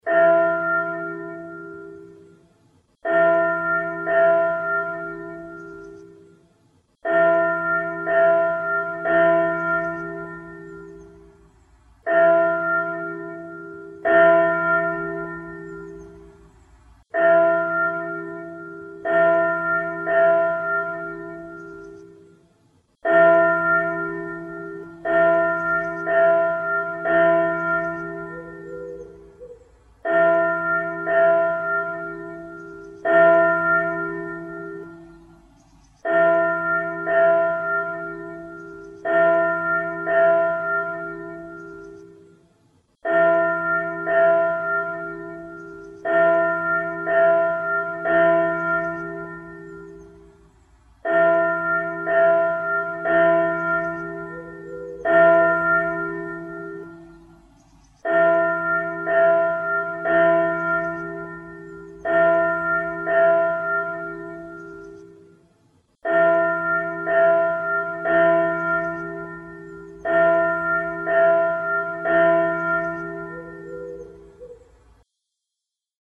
Klok
Daarom dat ik maar eens een wat beknopter systeem heb bedacht, dat gebruik maakt een van pauzes tussen de bimmen & bammen.
bimbam.mp3